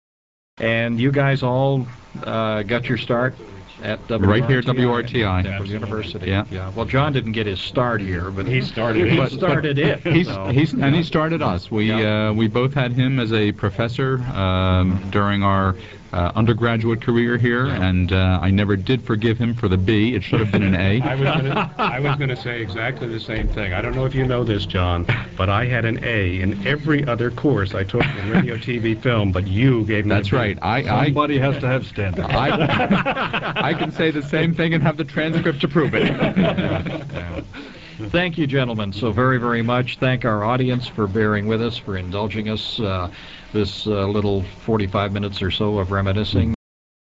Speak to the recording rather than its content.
Here are excerpts from that broadcast: